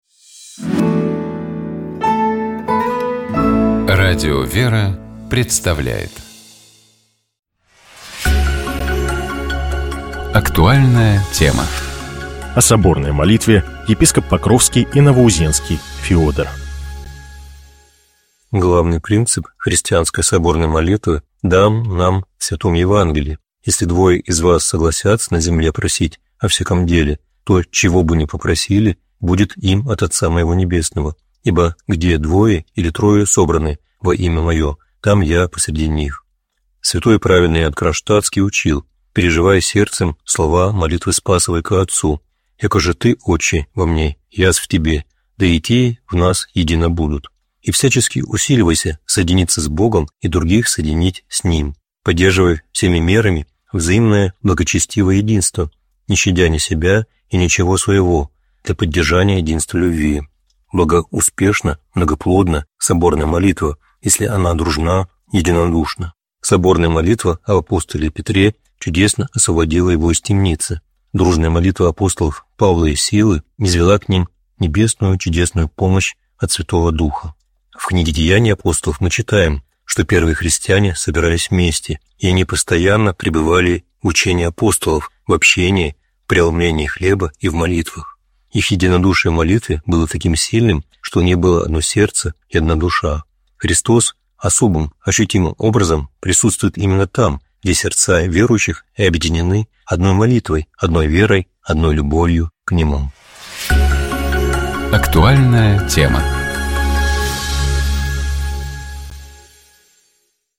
Псалом 114. Богослужебные чтения